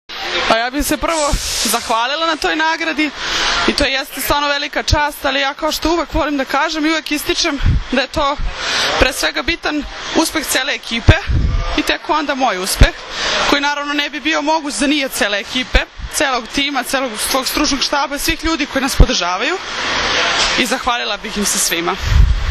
Na beogradskom aerodromu “Nikola Tesla”, srebrne juniorke su sa cvećem dočekali predstavnici Odbojkaškog saveza Srbije.
IZJAVA